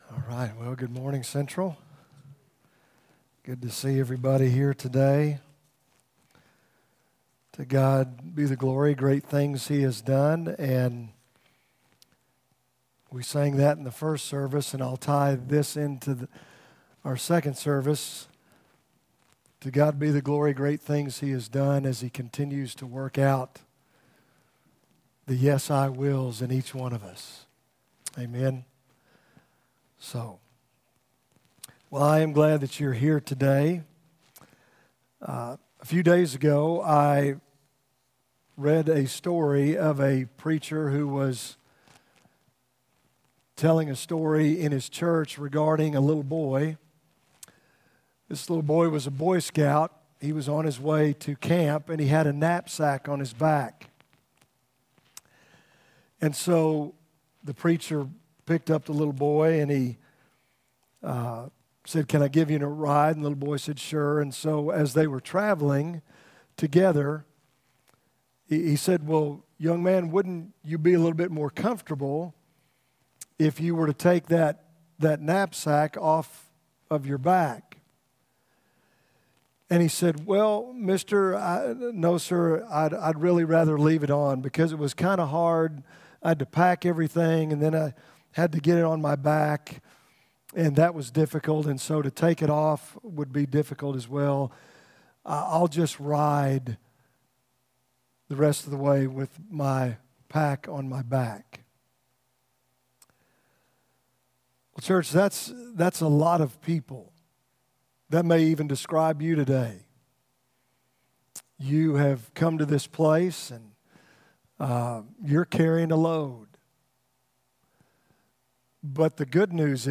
From Series: "2019 Sermons"